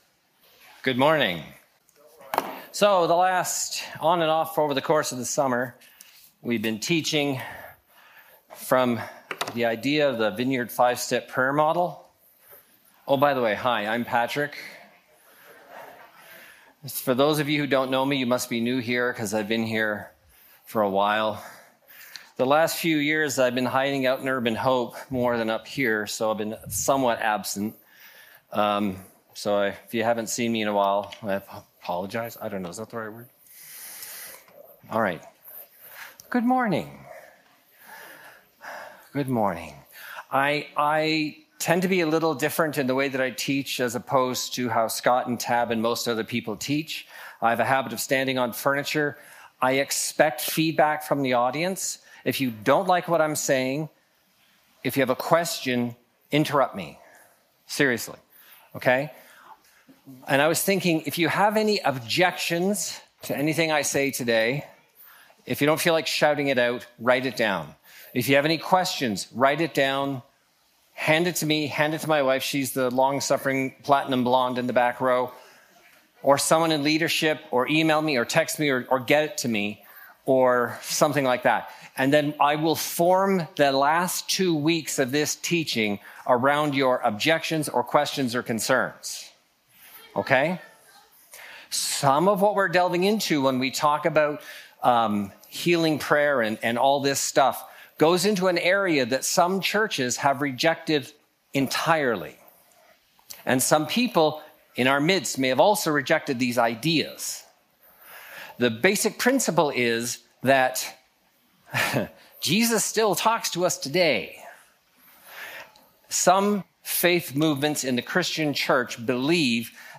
We will quickly review the entire model and then spend some time discussing what to do when we are done praying. Followed by a practice demonstration and time to practice what we have learned.